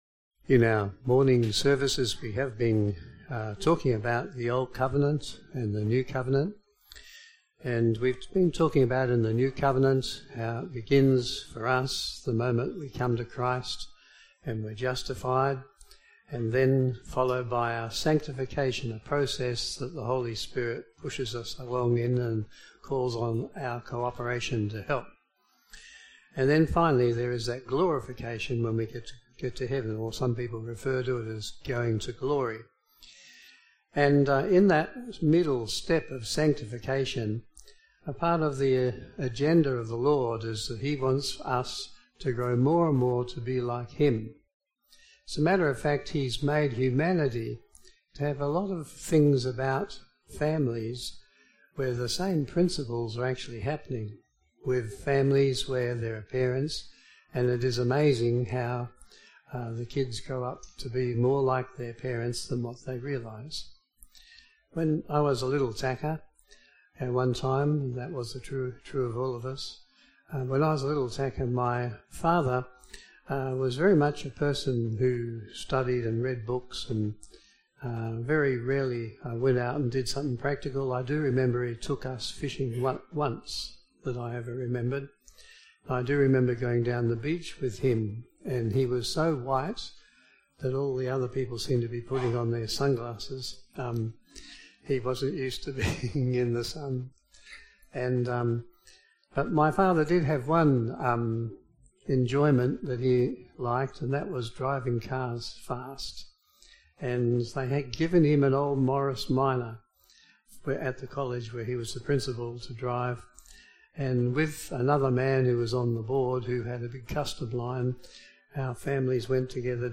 Galatians 3:1-29 Service Type: AM Service Automatically Generated Transcript You know